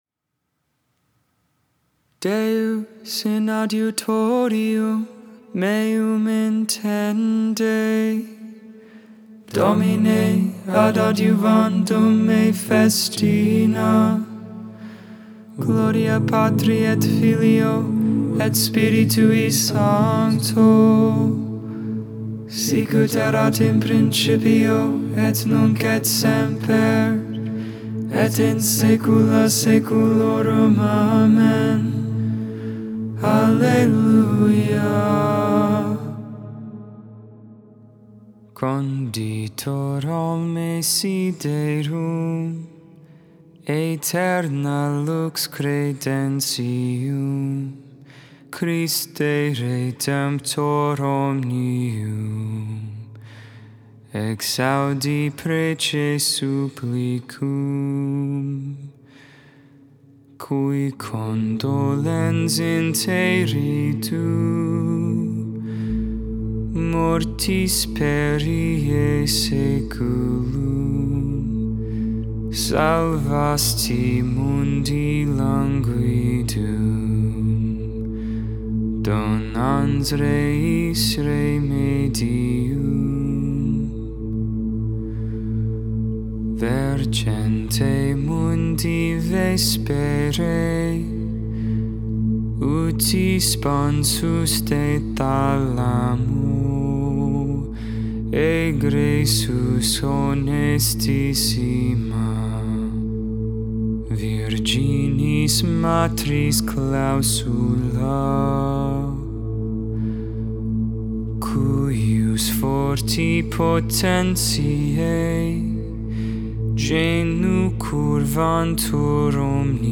Vespers, Evening Prayer for the 1st Friday in Advent, December 2nd, 2022.